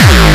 VEC3 Bassdrums Dirty 13.wav